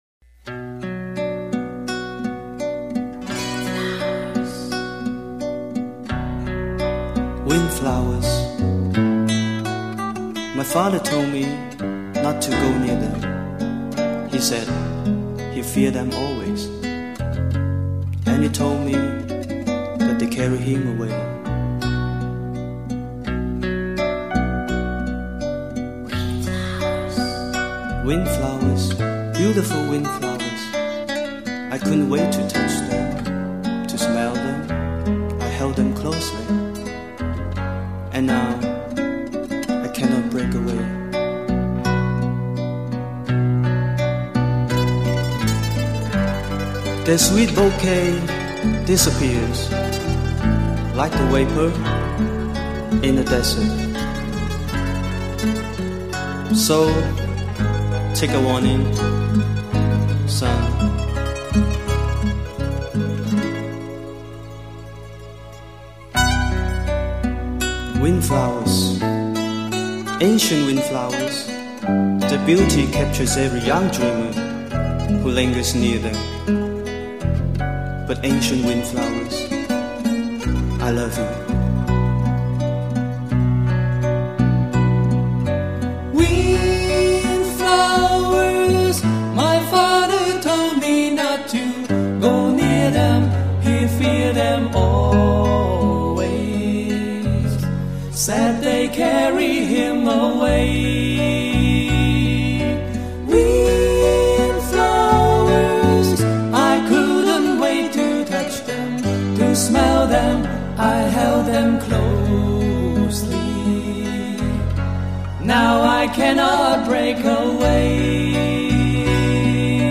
轻轻吟唱的男声似从心底发出悲鸣......
而轻轻拔动的吉他 宛如薄薄的风
英文吉他弹唱试听